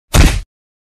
Hiệu ứng âm thanh Cú đá (2)